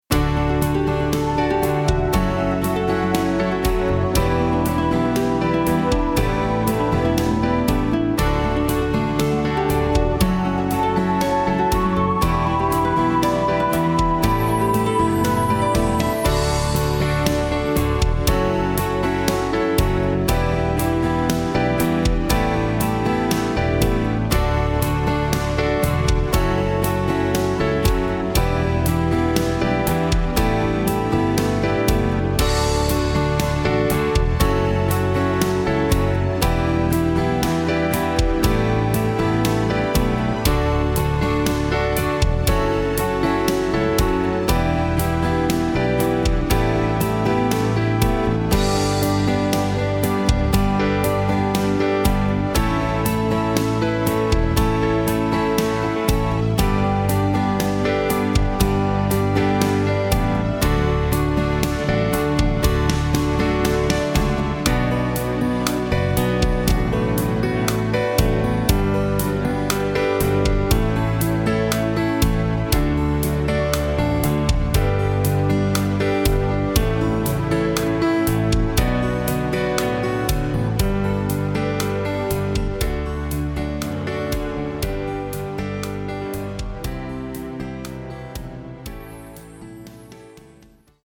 The third arrangement was a “pop” version that I ended up disliking (I’m not big on percussion).
you-are-my-wings-pop-blog-excerpt.mp3